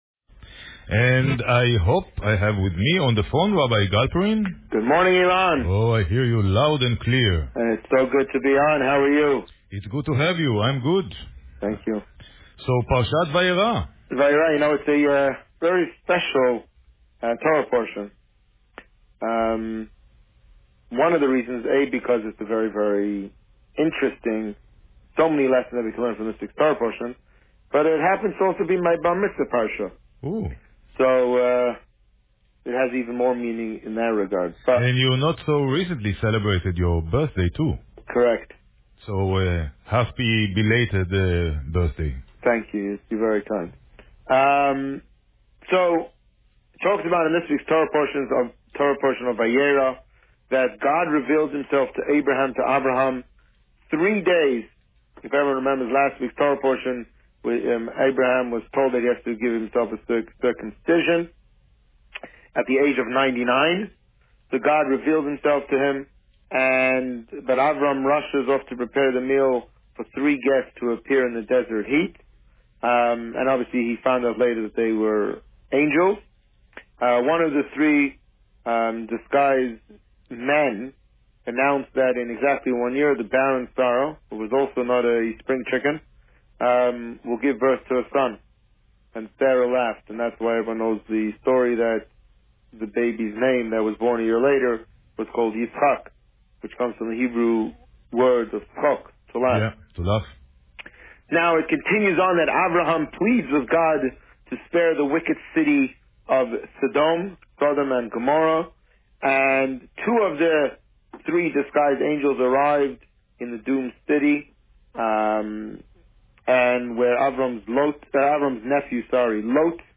The Rabbi on Radio
This week, the Rabbi spoke about Parsha Vayeira. Listen to the interview here.